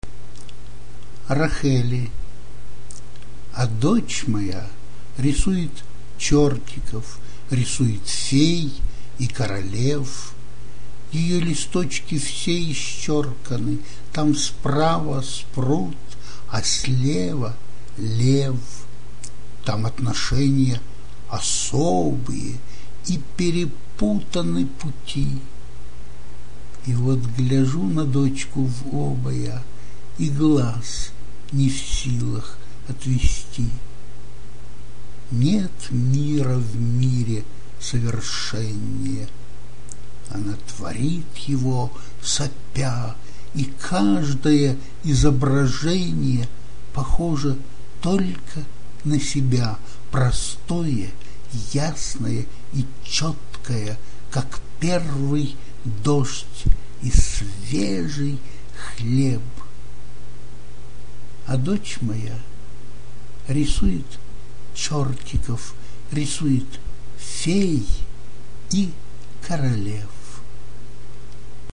ПРОСЛУШАТЬ В ИСПОЛНЕНИИ АВТОРА